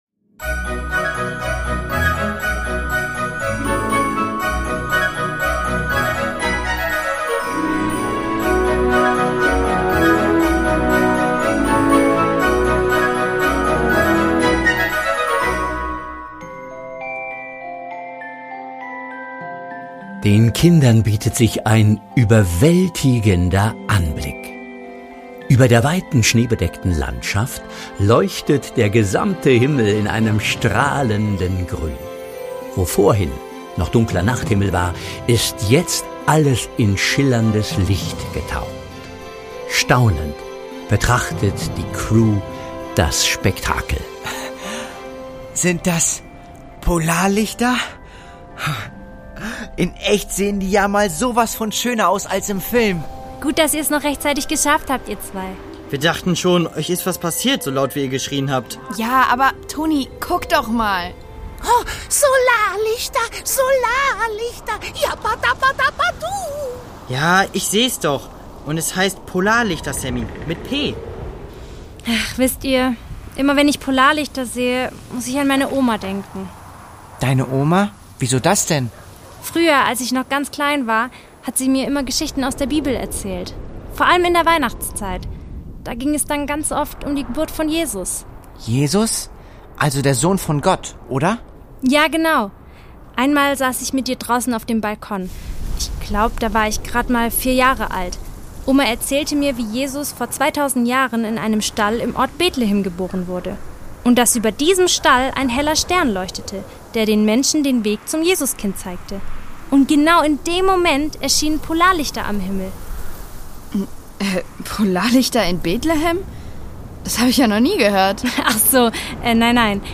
Lappland: Ein Rätsel im Schnee (6/24) | Die Doppeldecker Crew | Hörspiel für Kinder (Hörbuch)